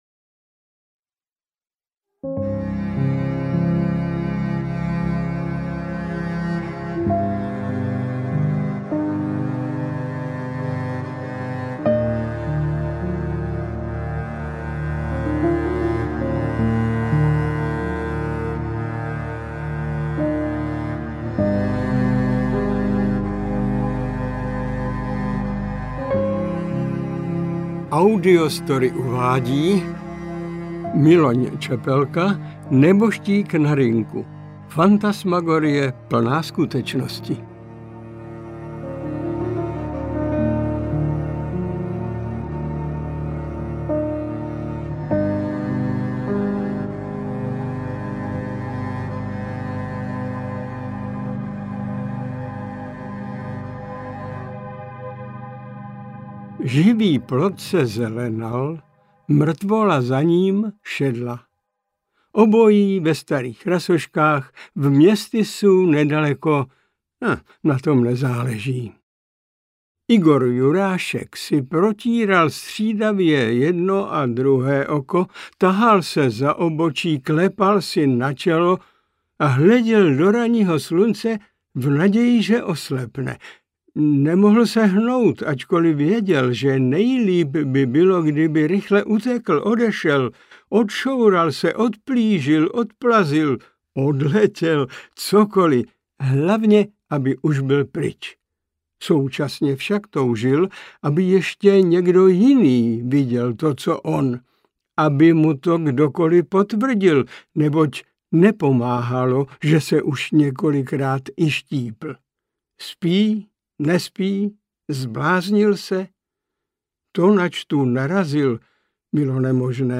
Interpret:  Miloň Čepelka